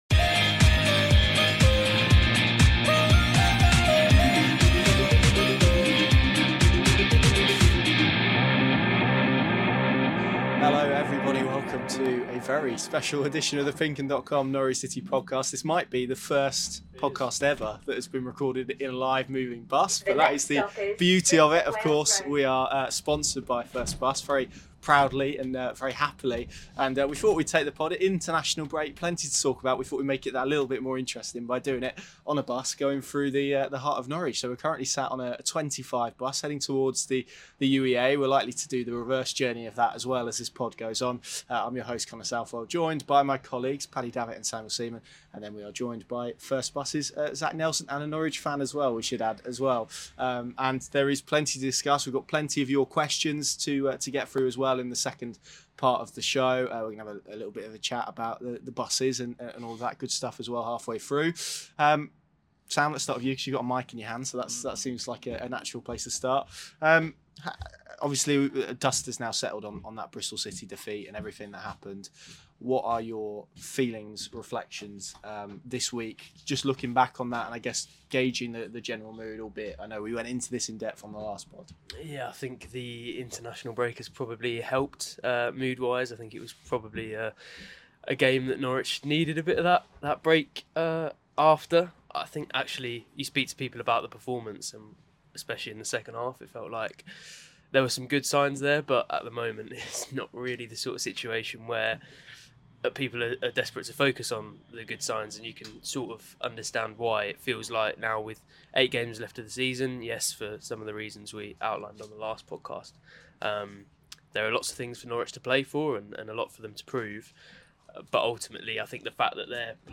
on the board First Bus number 25 heading through the centre of Norwich.